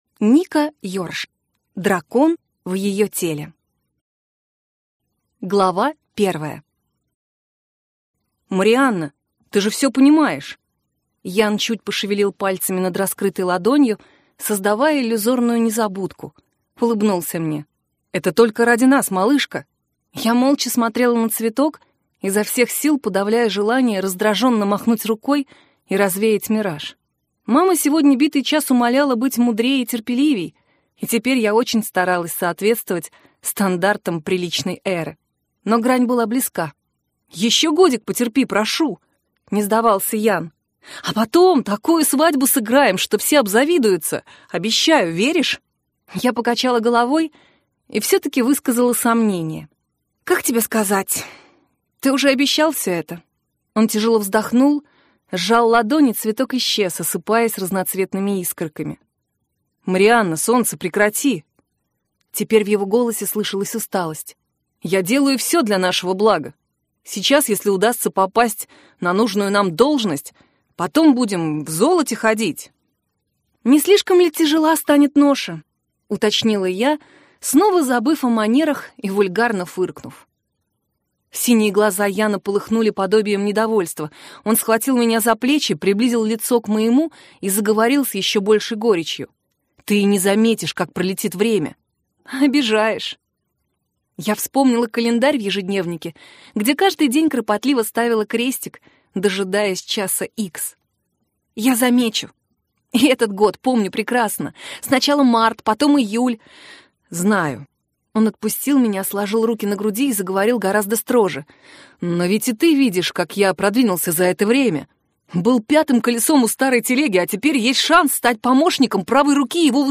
Аудиокнига Дракон в ее теле - купить, скачать и слушать онлайн | КнигоПоиск